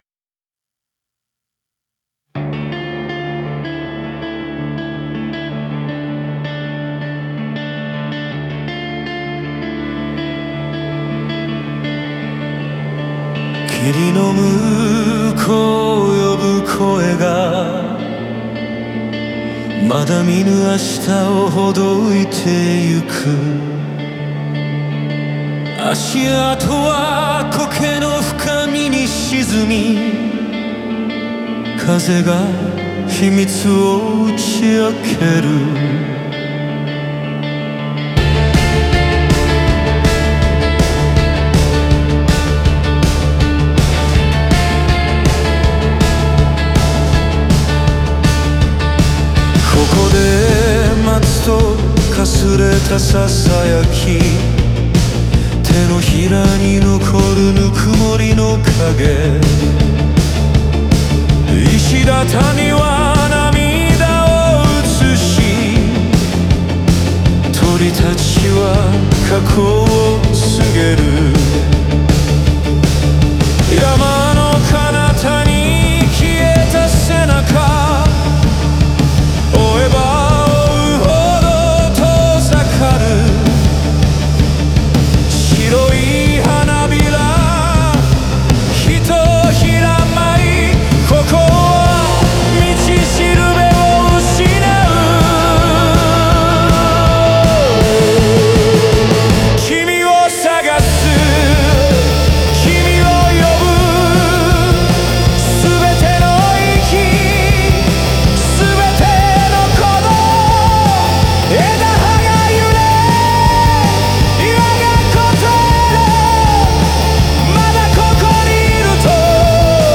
静かで冷ややかな始まりから、執念のように繰り返されるサビへと展開し、やがて山そのものが歌うようにクライマックスを迎える。